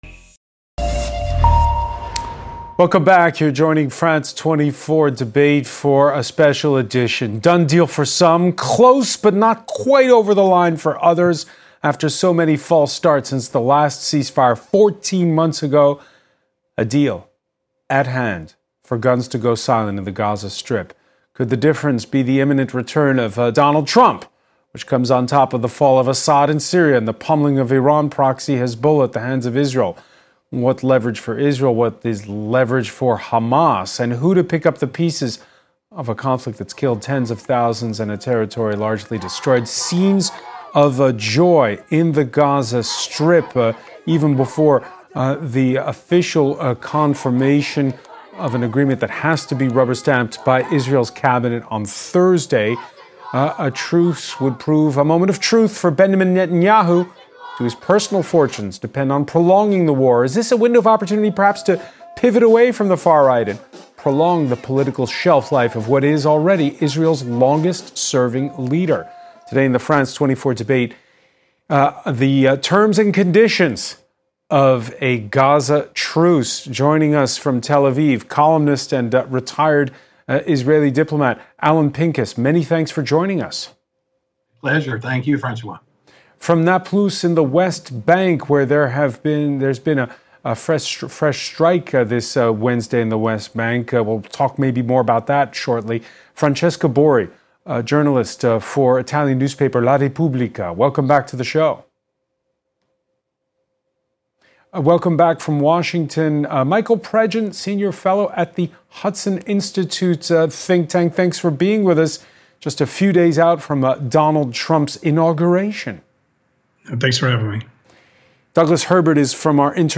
A live debate on the topic of the day, with four guests.